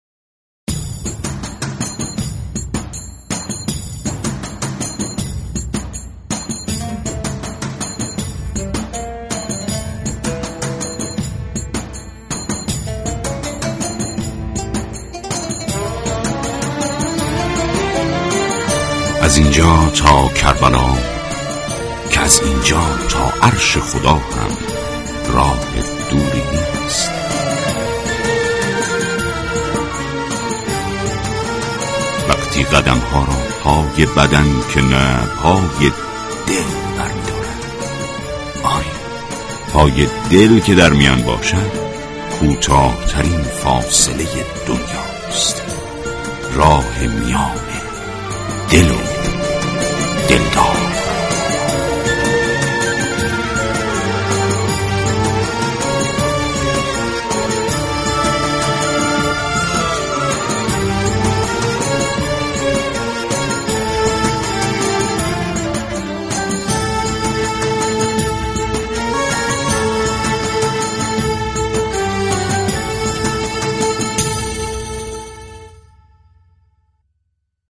رادیو صبا در ویژه برنامه" دل و دلدار "به مناسبت سالروز شهادت امام رضا (ع)همراه مخاطبان با ارتباط تلفنی به زیارت امام رضا (ع) می پردازد.
به گزارش روابط عمومی رادیو صبا ،" دل و دلدار "عنوان ویژه برنامه زنده عصرگاهی است كه به مناسبت ایام پایانی ماه صفر راهی آنتن صبا می شود.
دل و دلدار با ارتباط تلفنی و پخش گزارش های ارسالی از حرم مطهر علی ابن موسی الرضا (ع) همراه مخاطبان به زیارت امام هشتم می رود.